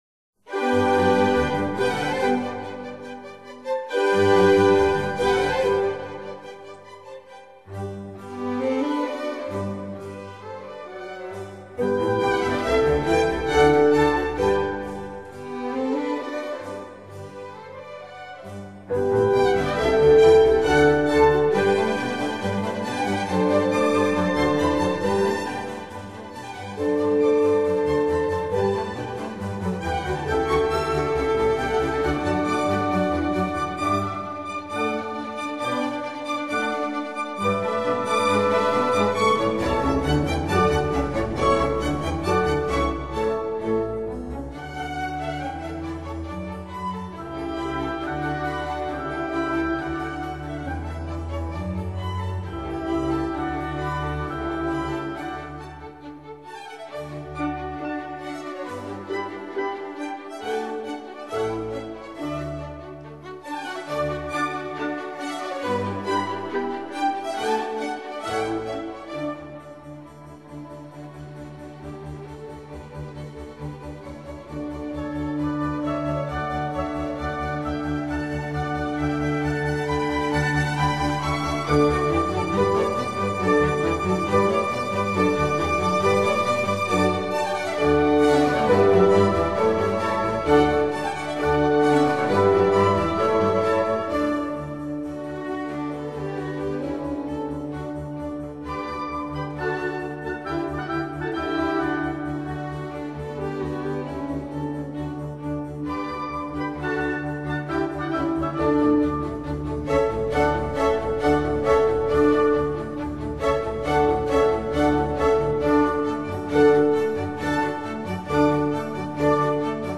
管弦交響樂輯